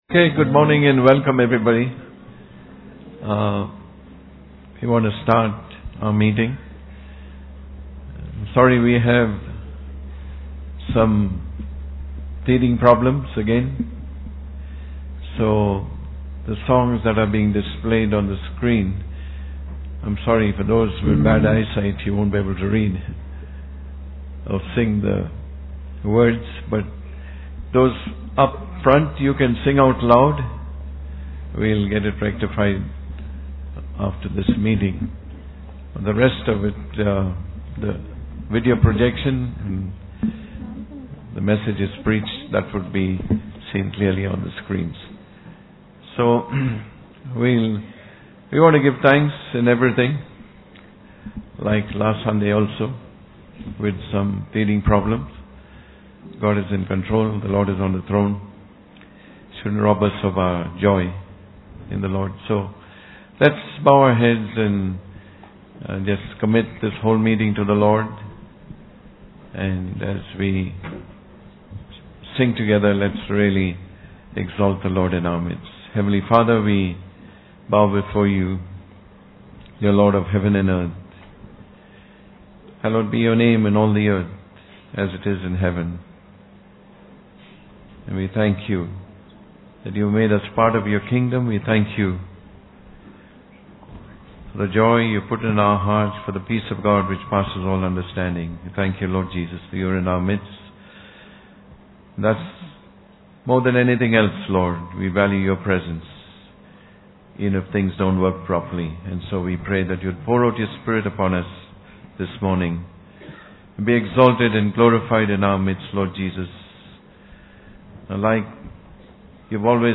the-church-dedication-service.mp3